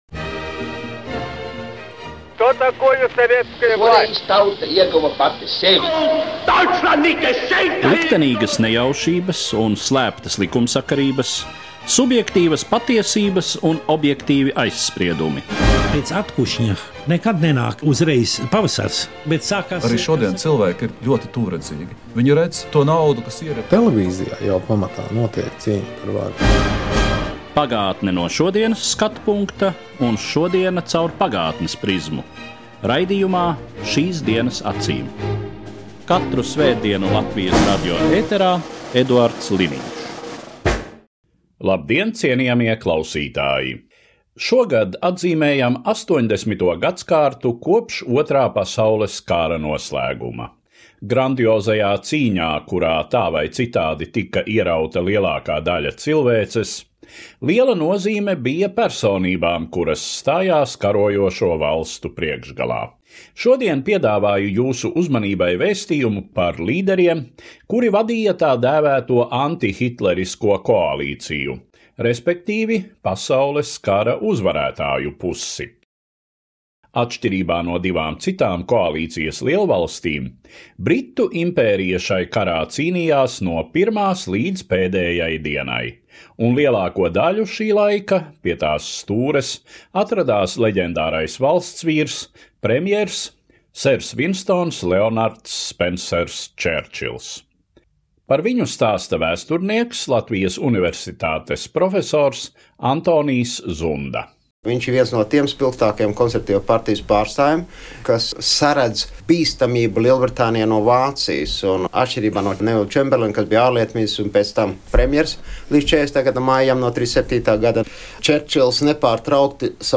16. oktobrī Rīgas pilsētas vadība lēma par dažām izmaiņām Rīgas pilsētvidē, proti, runa ir par vairākiem pieminekļiem, kas vai nu pazudīs, vai, stipri izmainīti turpmāk varētu būt Rīgas vidē skatāmi. Par visu šo pieminekļu situāciju un ko mums no tā būs mācīties un secināt saruna raidījumā Šīs dienas acīm.